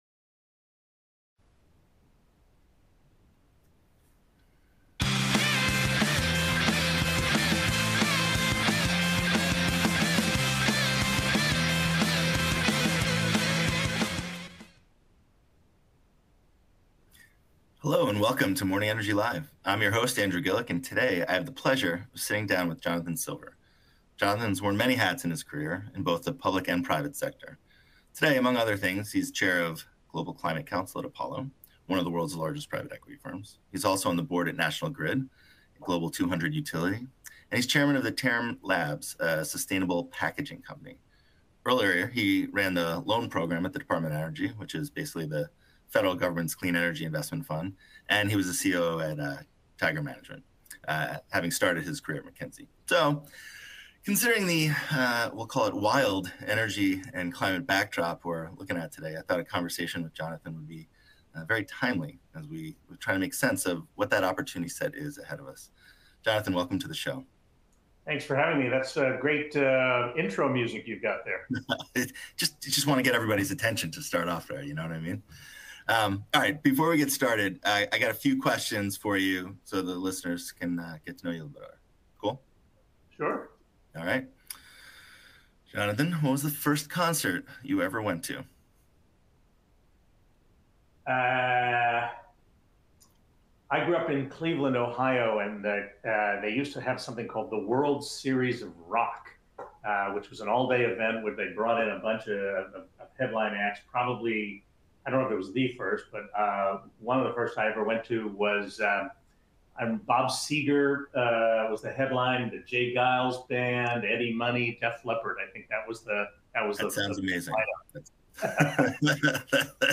Every month, we will release new episodes featuring 30-minute segments with interviews of some of the most insightful and influential figures in today’s industry.